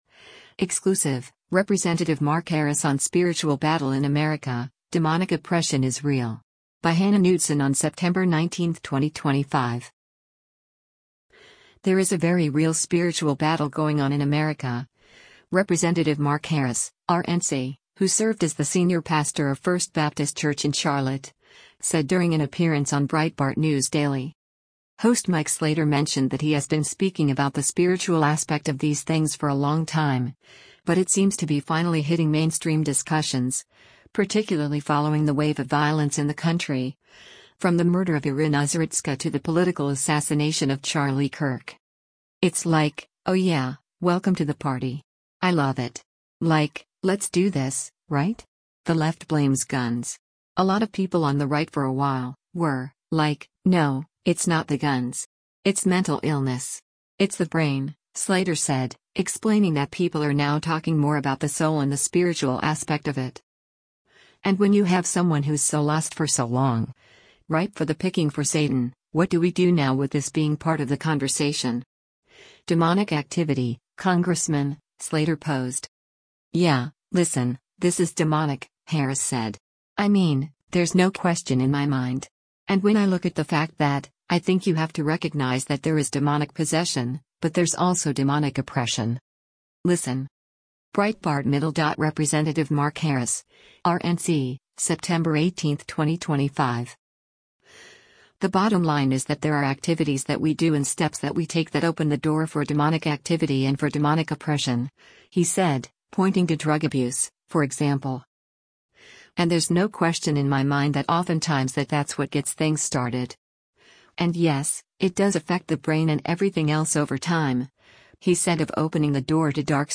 There is a very real spiritual battle going on in America, Rep. Mark Harris (R-NC) — who served as the senior pastor of First Baptist Church in Charlotte — said during an appearance on Breitbart News Daily.